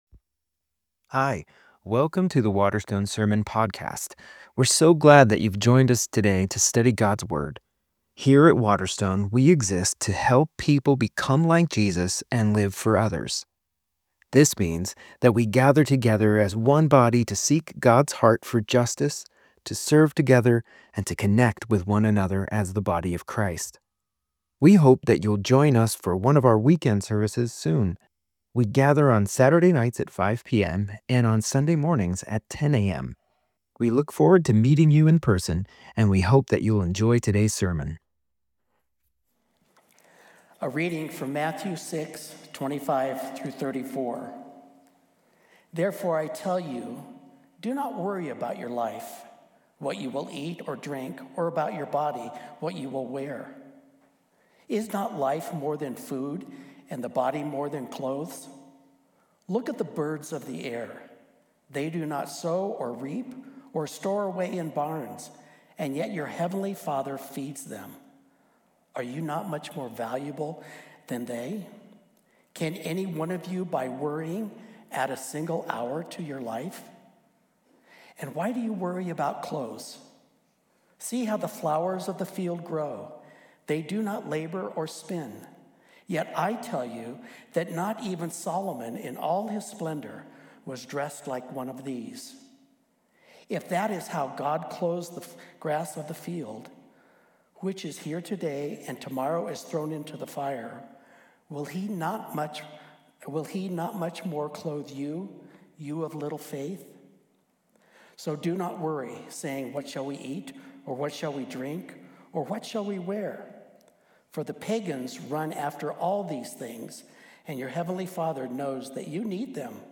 This sermon reveals that when Jesus says "don't worry", he's not being dismissive, but inviting us to stop trying to serve money and stuff (Mammon) and put our total trust in God instead. We're encouraged to adopt a posture of open-handed dependence—like the birds and flowers—and to seek first his kingdom, knowing everything else we need will follow.